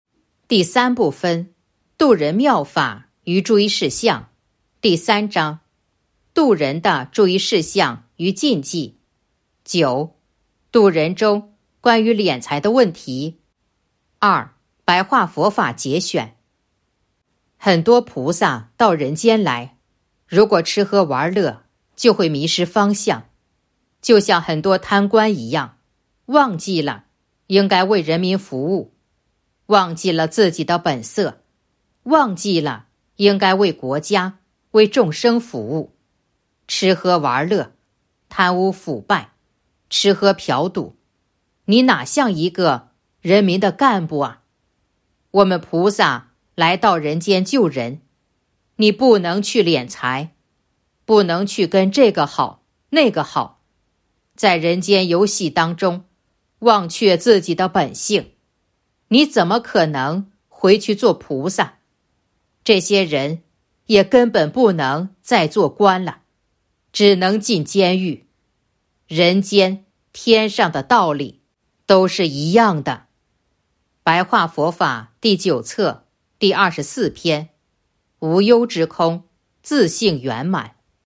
065.2. 白话佛法节选《弘法度人手册》【有声书】